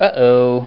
Uhoh Sound Effect
Download a high-quality uhoh sound effect.
uhoh.mp3